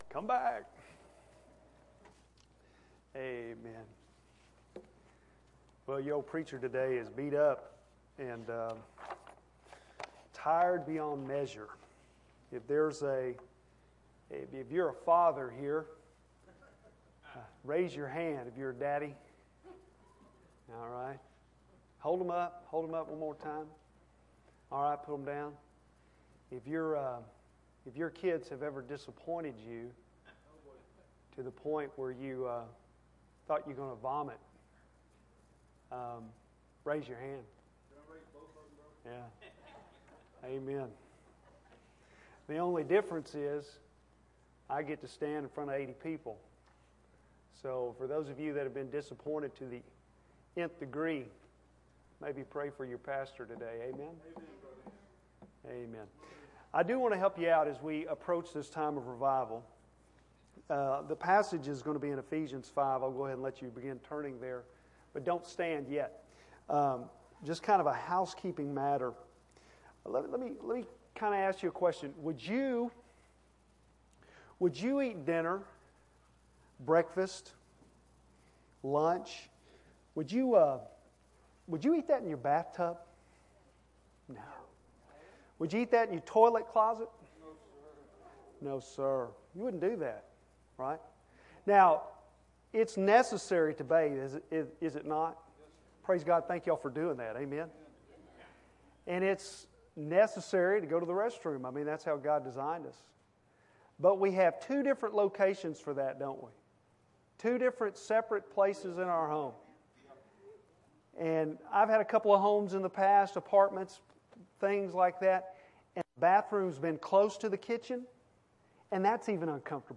Bible Text: Ephesians 5:1-3 | Preacher